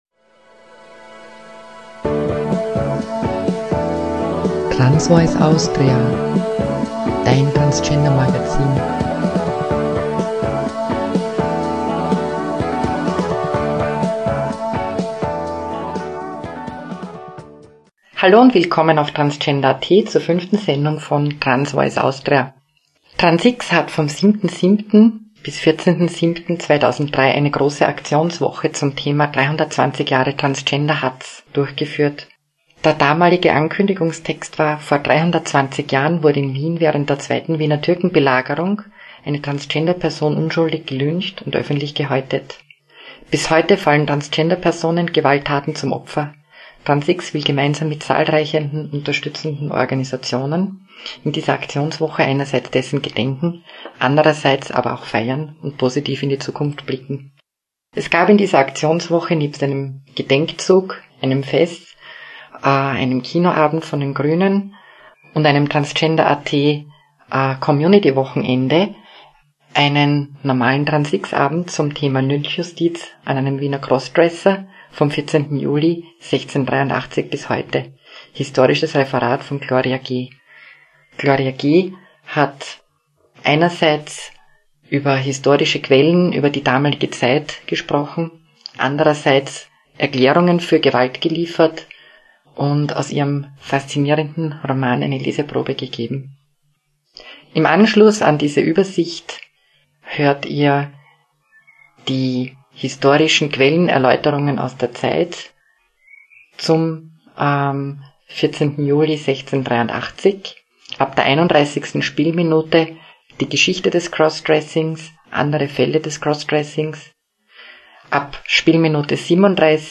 Ab Spielminute 0:03 Historisches Referat 0:31 Geschichte des Crossdressings 0:37 Transgendergewalt in �sterreich 0:46 Theorie zur Gewalt 0:59 Auswege aus der Gewalt 1:18 "Das 6.